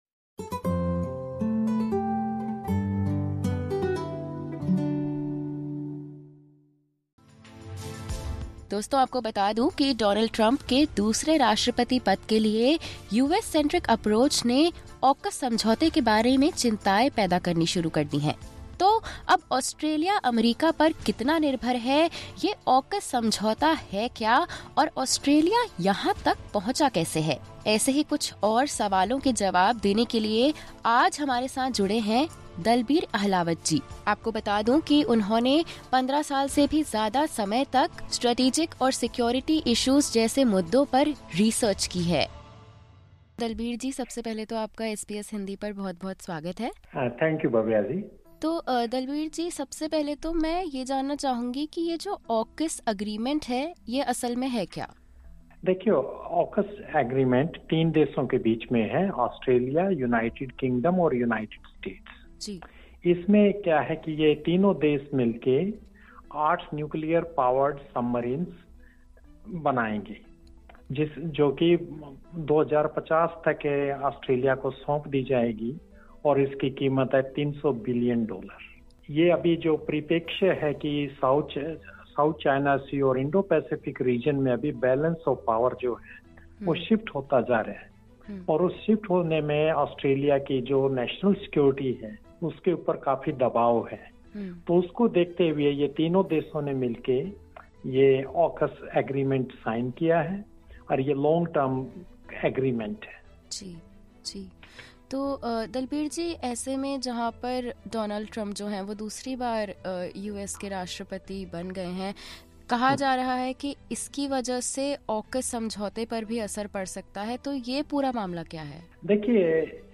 Disclaimer: The views expressed in this podcast episode are those of the interviewee and do not reflect the views of SBS Hindi.